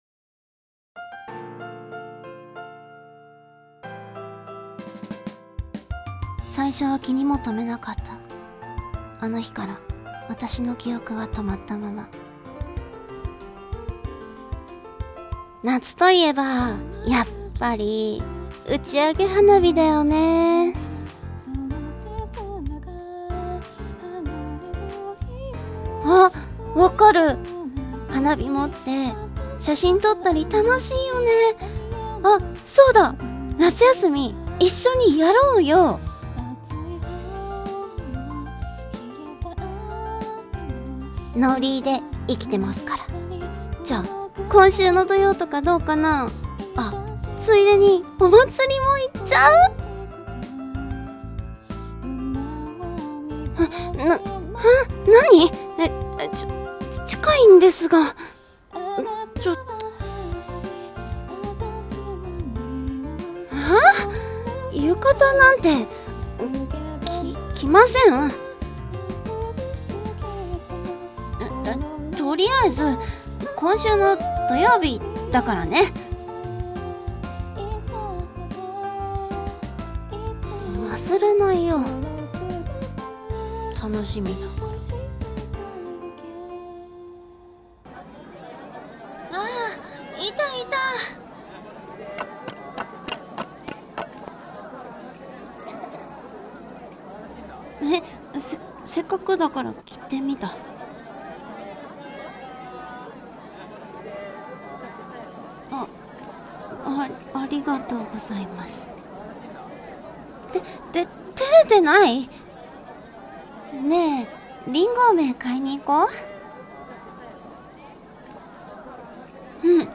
【二人声劇】線香花火と君。